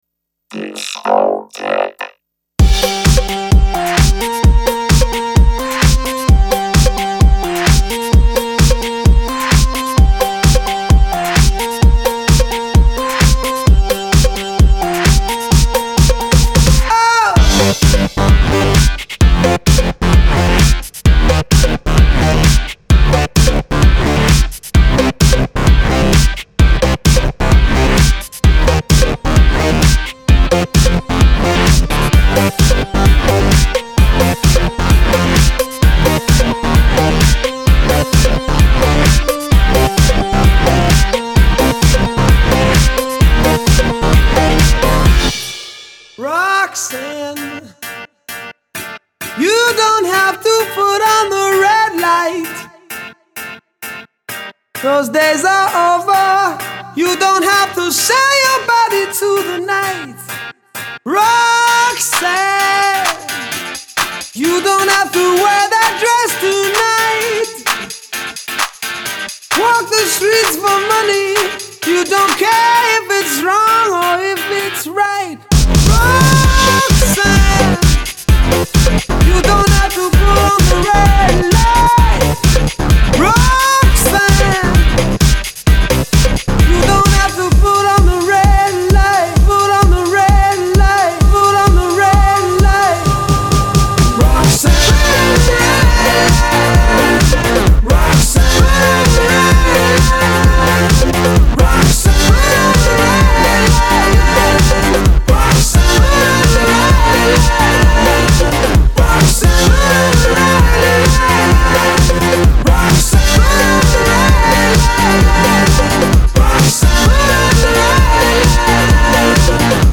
para las salas de baile. En versión disco: